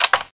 00043_Sound_CLICK2